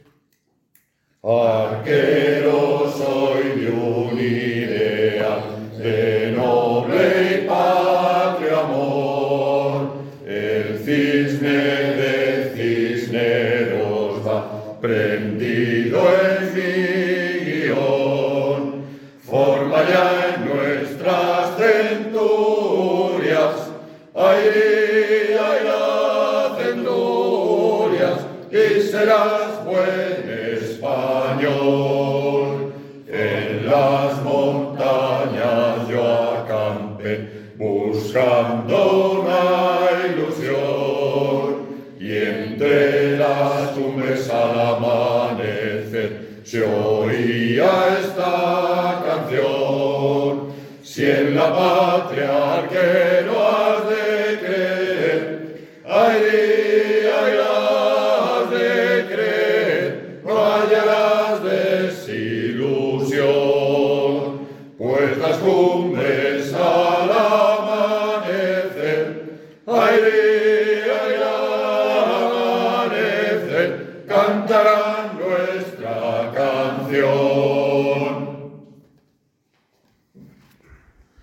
La letra es de Antón Riestía Pita, y la música de una marcha alemana.